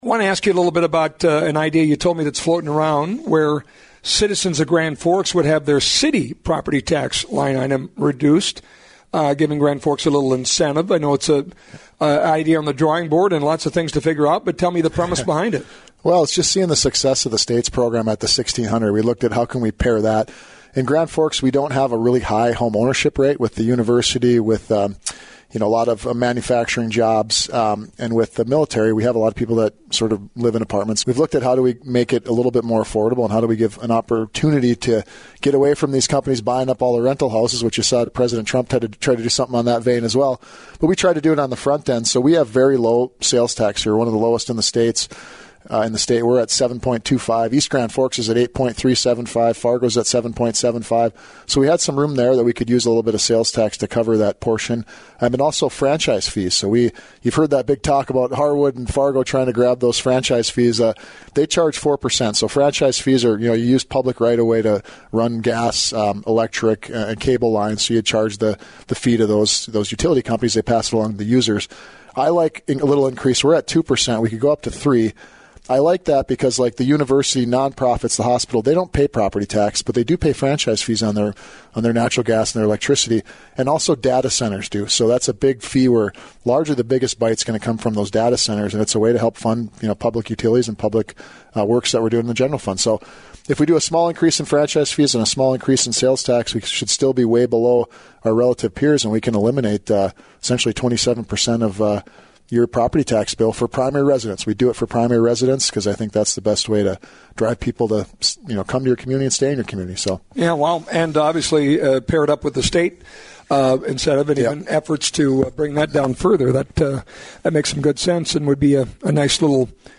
LISTEN: Grand Forks Mayor Brandon Bochenski’s conversation